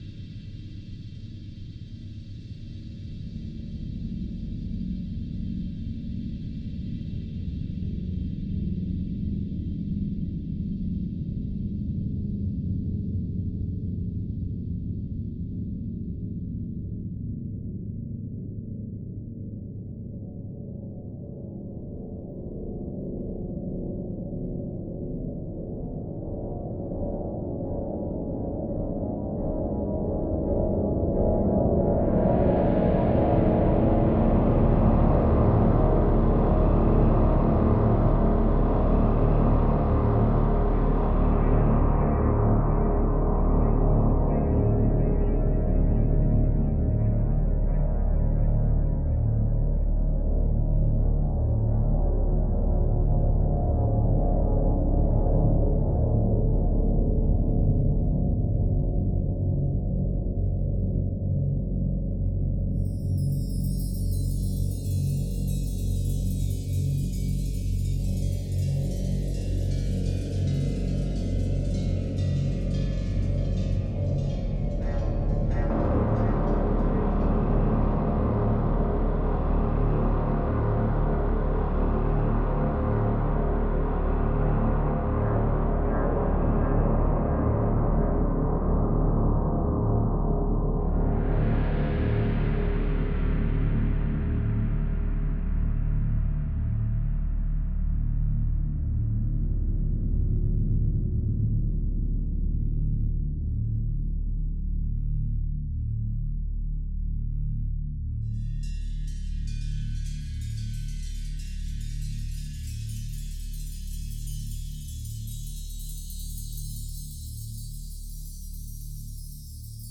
Эмбиент Ambient Музыка ambient